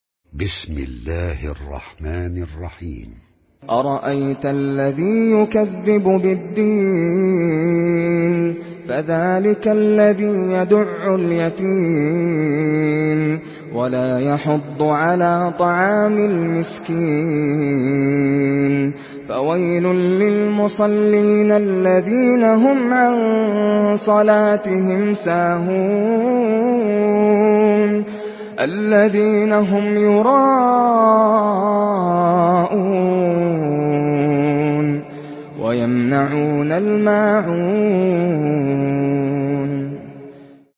Surah Al-Maun MP3 by Nasser Al Qatami in Hafs An Asim narration.
Murattal Hafs An Asim